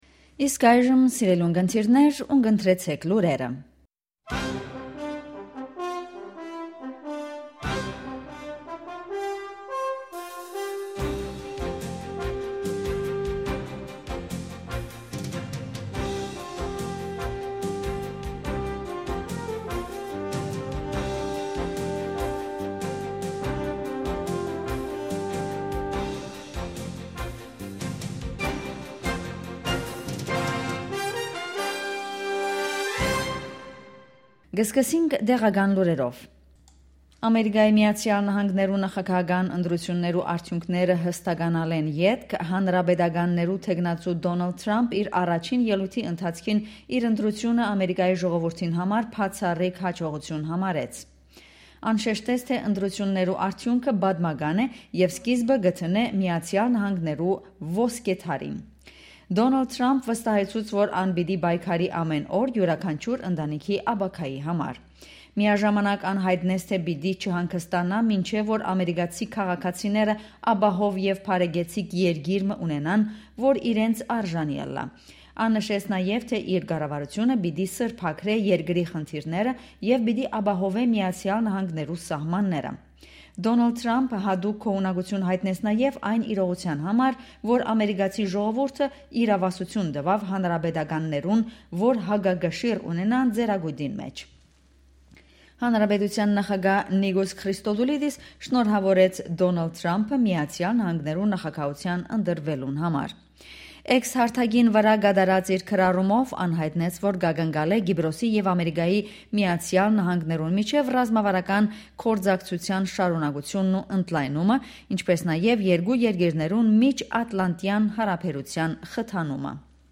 Ειδήσεις στα Αρμένικα - News in Armenian
Daily News in Armenian.